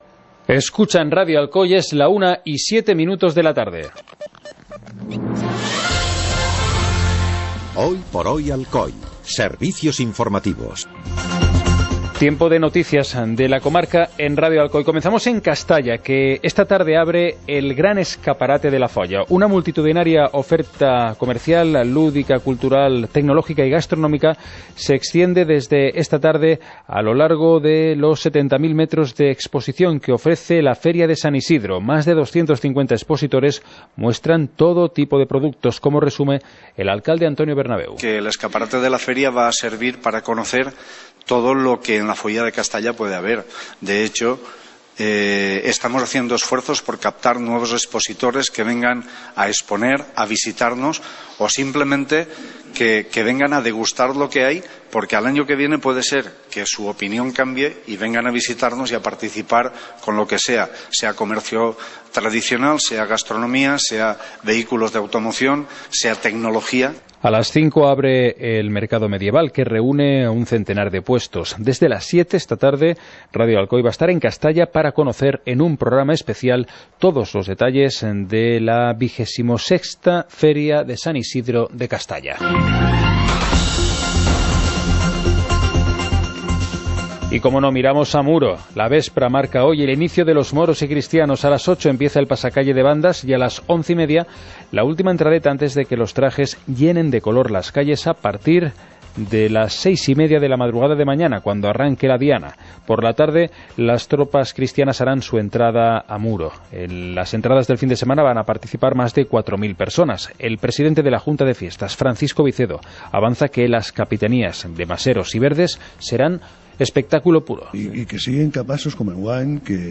Informativo comarcal - viernes, 11 de mayo de 2018